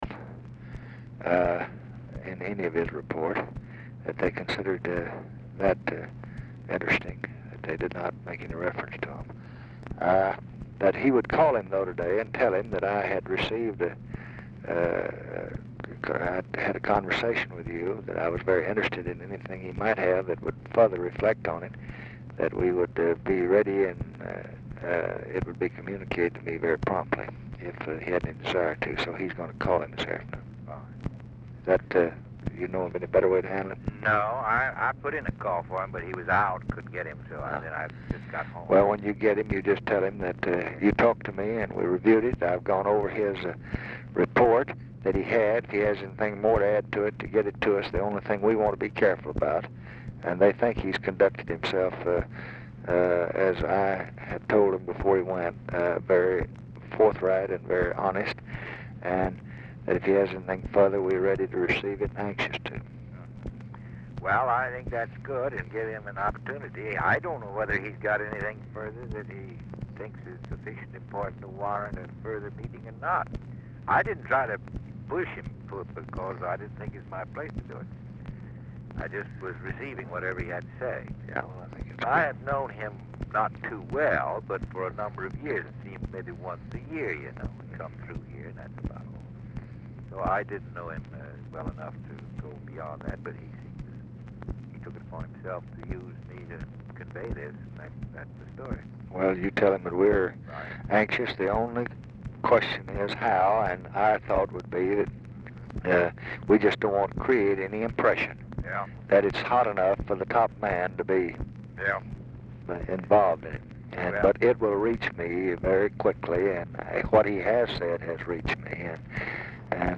Telephone conversation # 11416, sound recording, LBJ and WILLIAM FULBRIGHT
RECORDING STARTS AFTER CONVERSATION HAS BEGUN
Format Dictation belt
Location Of Speaker 1 Mansion, White House, Washington, DC